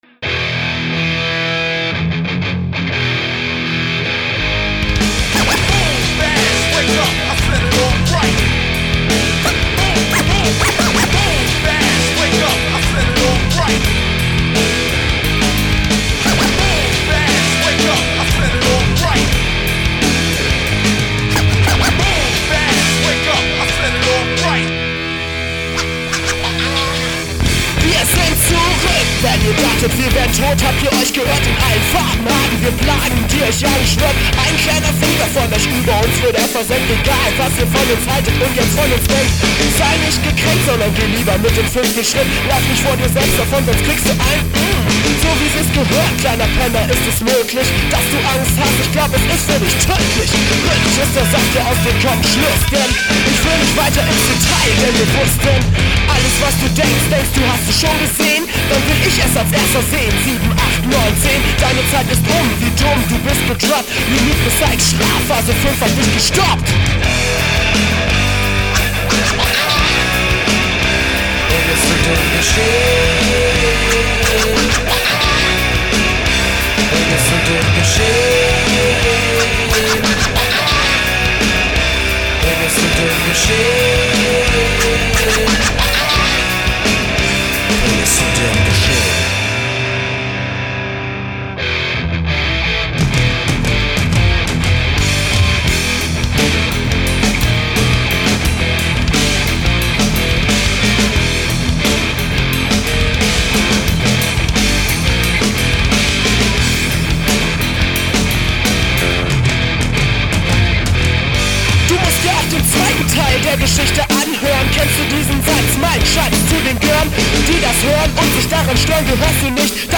такие-то вертушки.